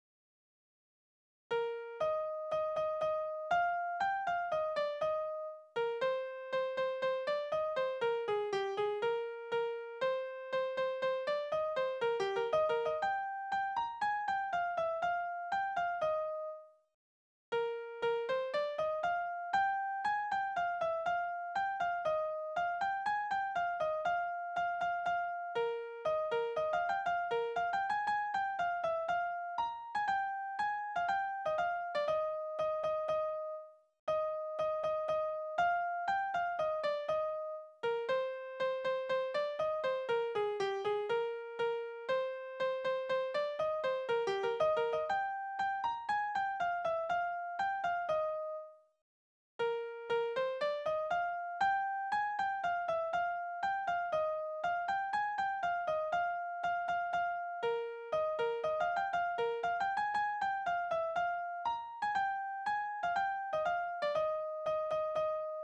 Soldatenlieder: Ansbacher Dragoner